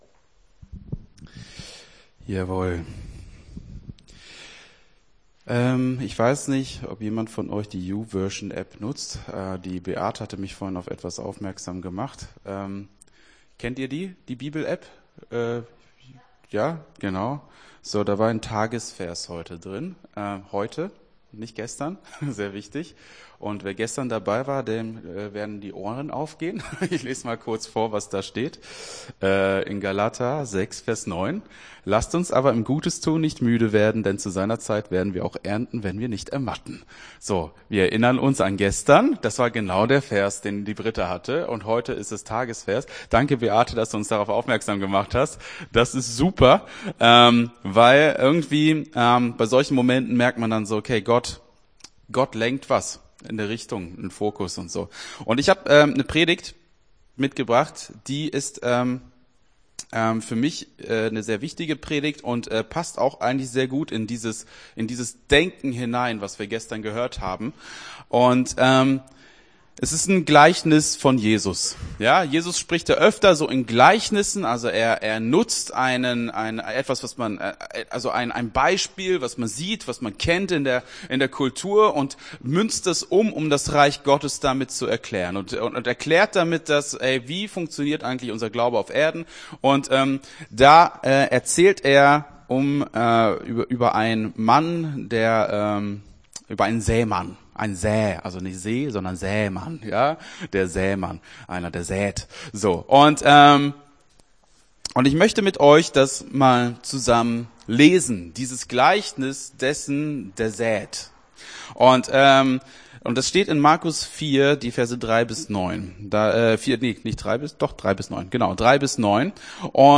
Gottesdienst 06.11.22 - FCG Hagen